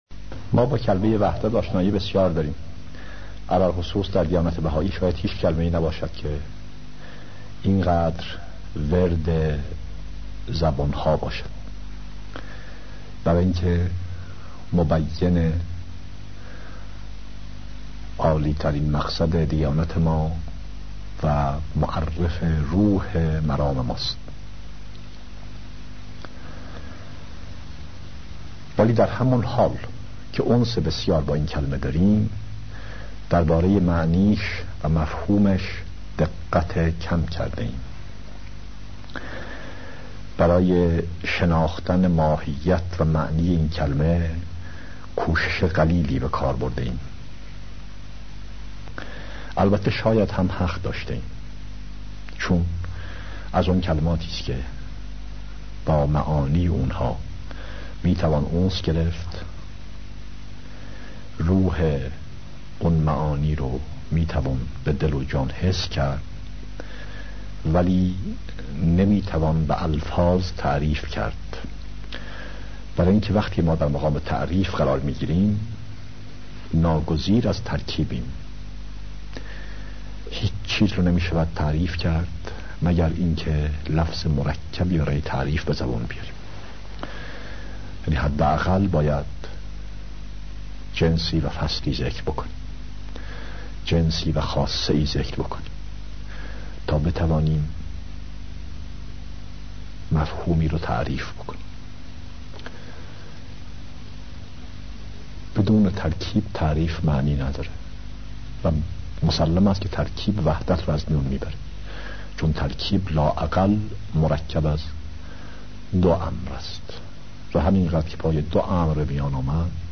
دانلود 062 VAHDAT DAR KESRAT.MP3 سایر دسته بندیها سخنرانی هایی پیرامون عقاید بهائی 16000 بازدید افزودن دیدگاه جدید نام شما موضوع دیدگاه * اطلاعات بیشتر درباره قالب‌بندی متن چه کدی در تصویر می‌بینید؟